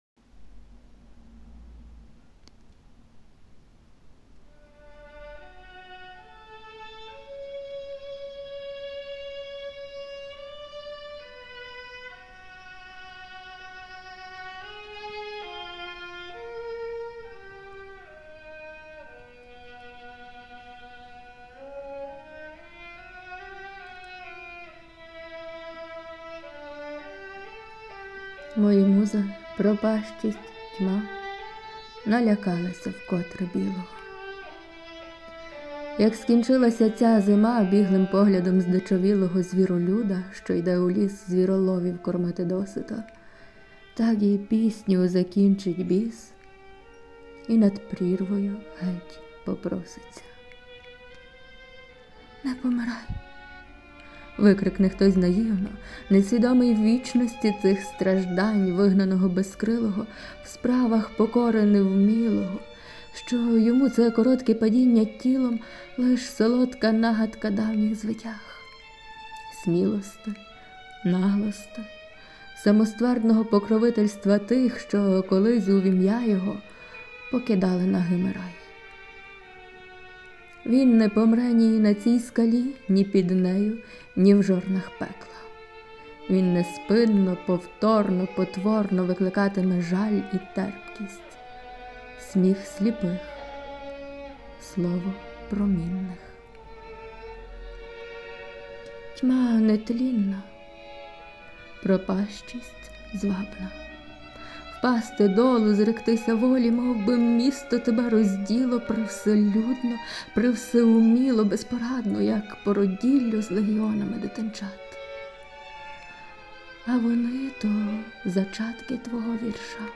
То є мій начитковий голос, в коментарях я пискливіша))
А супровід - мій улюблений Б.Барток, концерт для скрипки 1.
Але доводиться стримувати темп, щоб це реально було слухати)